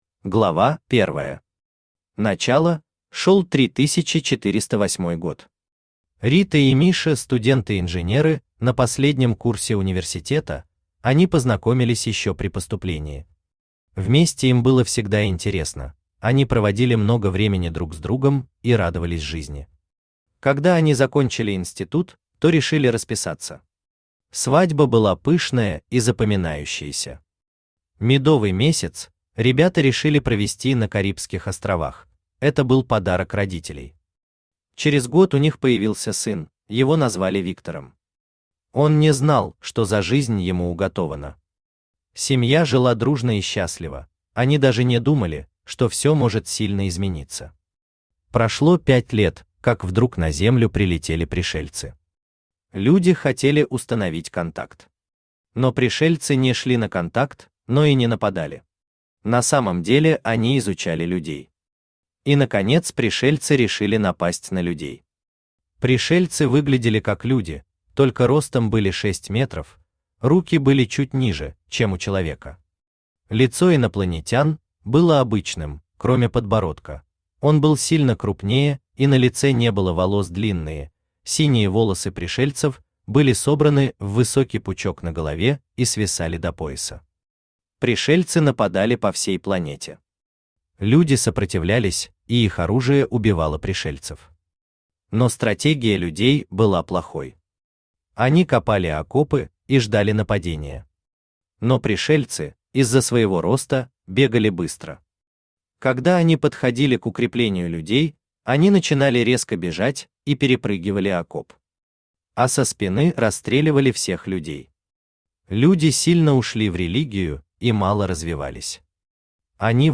Aудиокнига Вторая Земля Автор ALEX 560 Читает аудиокнигу Авточтец ЛитРес.